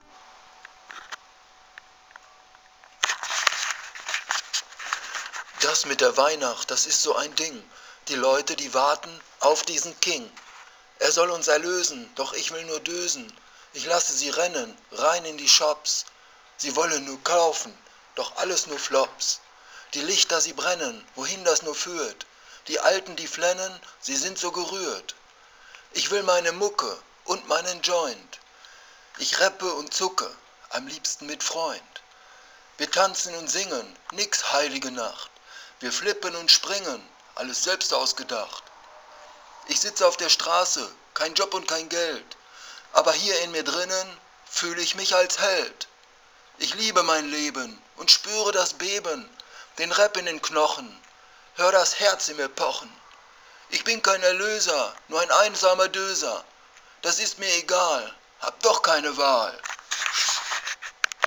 Weihnachtsrappsong